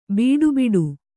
♪ bīḍu biḍu